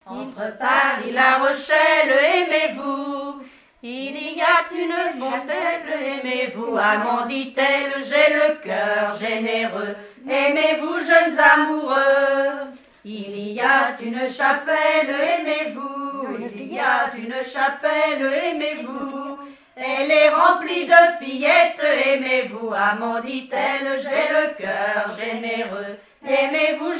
danse : ronde : rond de l'Île d'Yeu
Genre laisse
Témoignages sur la pêche, accordéon, et chansons traditionnelles
Pièce musicale inédite